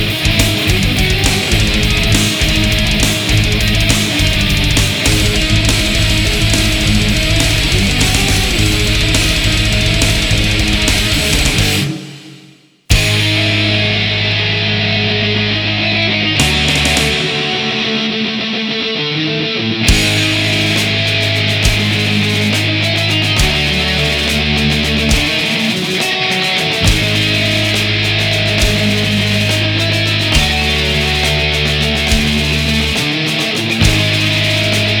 Жанр: Рок / Метал
Metal, Rock, Death Metal, Black Metal